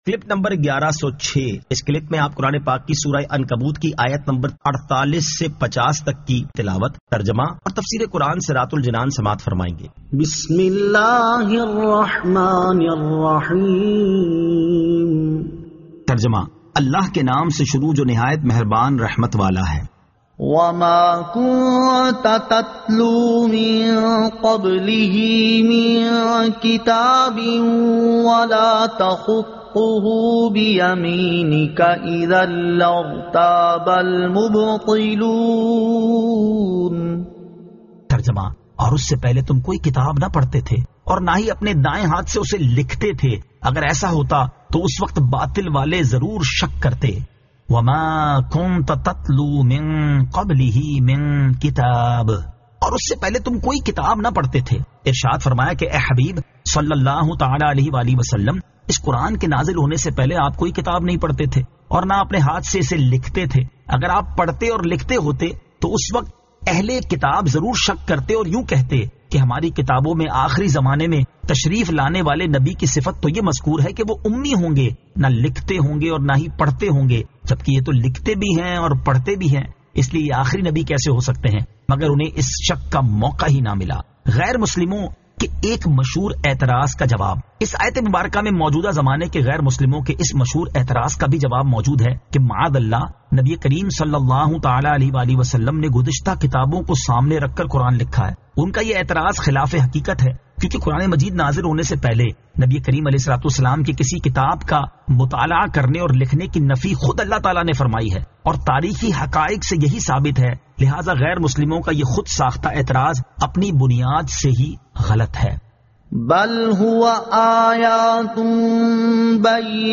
Surah Al-Ankabut 48 To 50 Tilawat , Tarjama , Tafseer